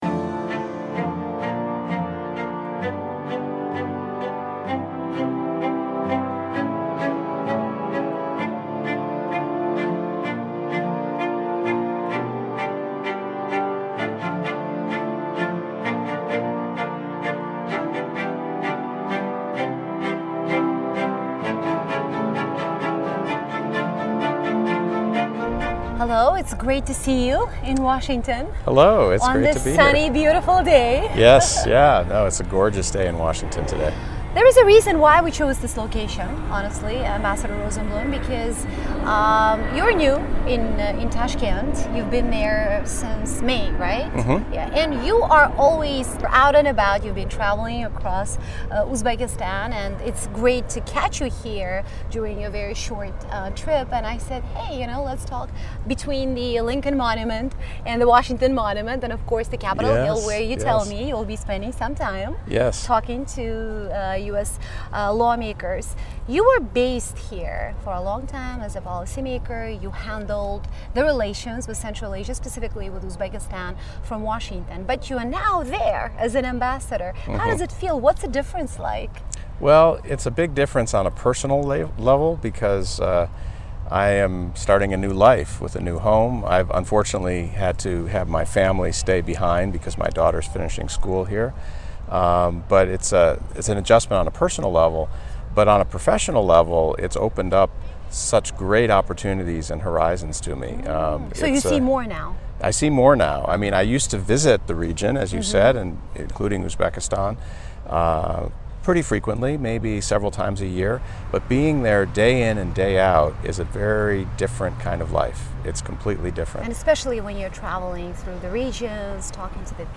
U.S.-Uzbekistan: Exclusive with Ambassador Daniel Rosenblum